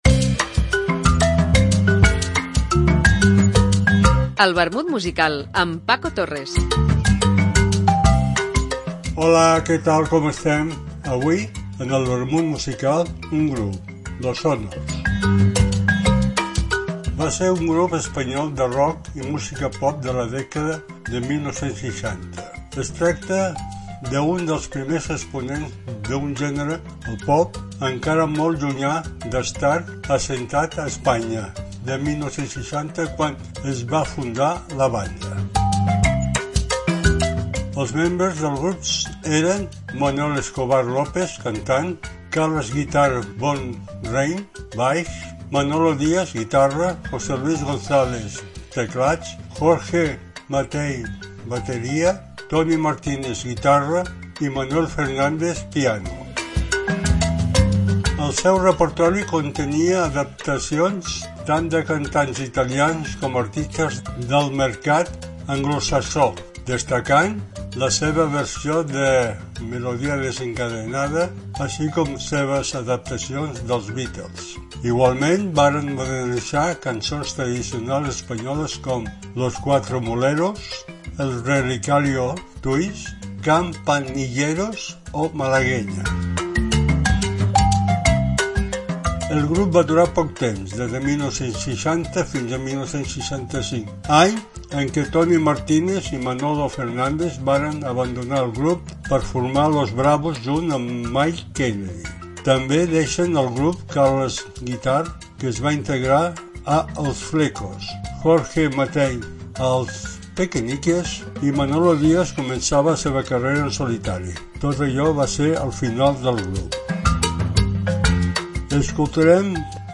Una apunts biogràfics acompanyats per una cançó